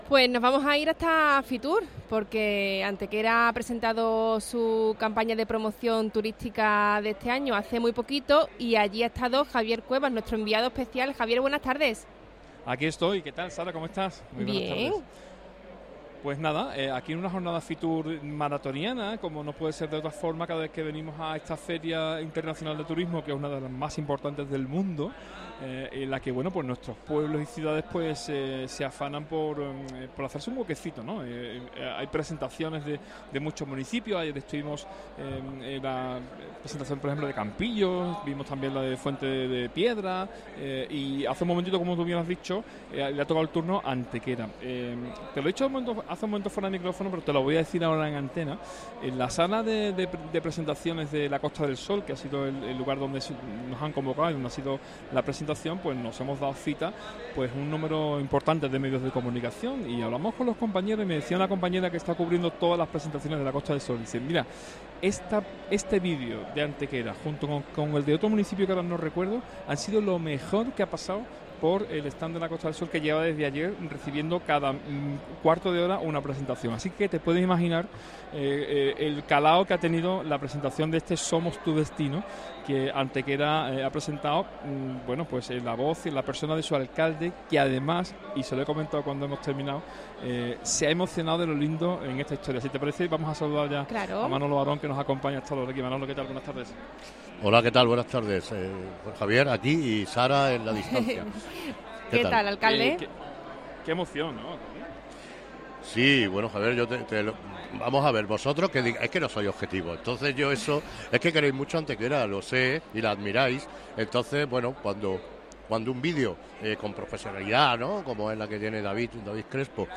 Entrevista Manuel Barón. Antequera en FITUR 2024.